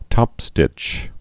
(tŏpstĭch)